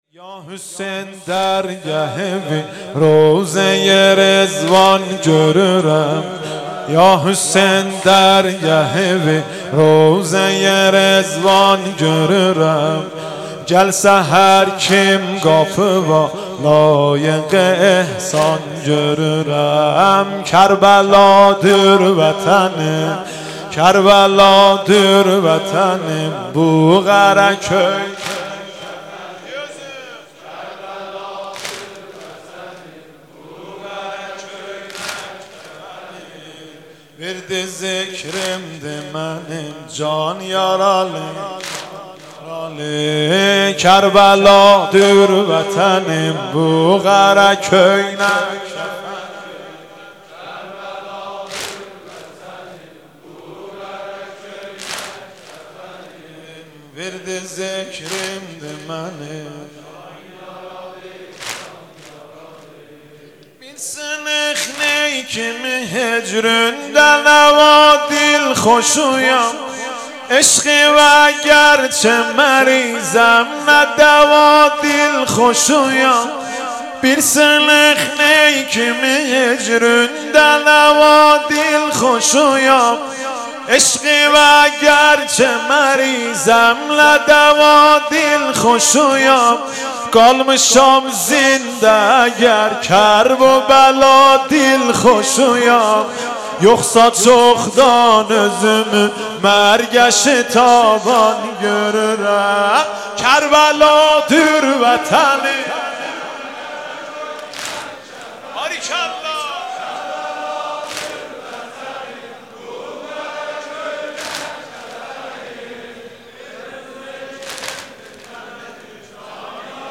واحد یا حسین درگهوی روضه رضوان گورورم با صدای حاج مهدی رسولی در شب اول محرم الحرام ۱۴۴۲هـــ
نوحه یا حسین درگهوی روضه رضوان گورورم با صدای حاج مهدی رسولی در شب اول محرم الحرام ۱۴۴۲هـــ (مصادف با پنجشنبه سی ام مرداد ماه ۱۳۹۹ ش) در هیئت ثارالله (رهروان امام و شهدا)